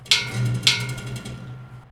RepairMetal.wav